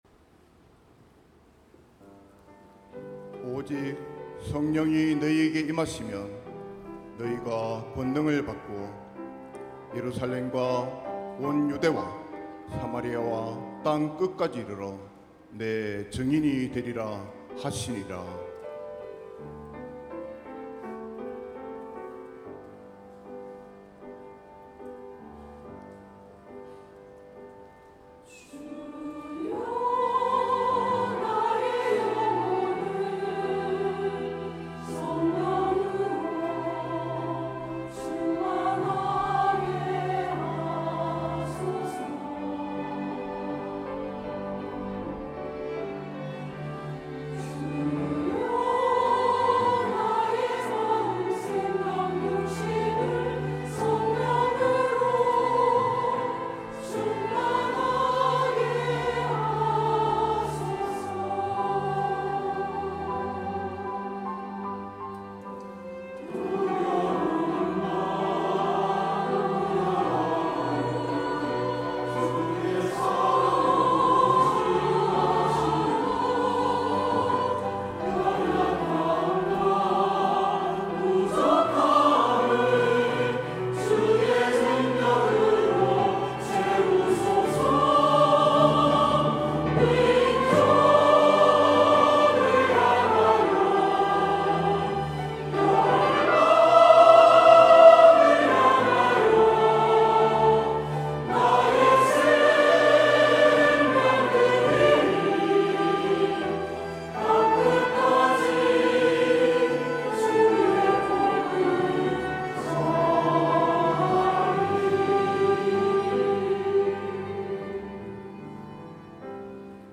2부 찬양대